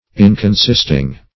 \In`con*sist"ing\